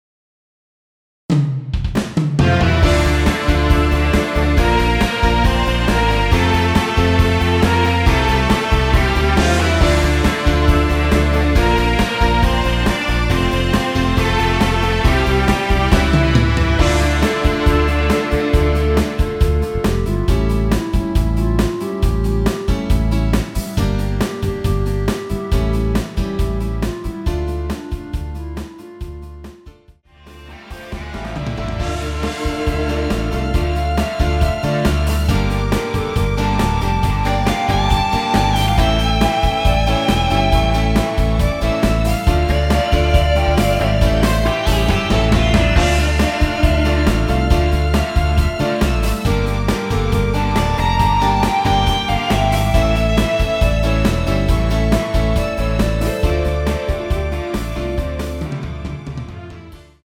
원키 멜로디 포함된 MR입니다.
Dm
앞부분30초, 뒷부분30초씩 편집해서 올려 드리고 있습니다.
중간에 음이 끈어지고 다시 나오는 이유는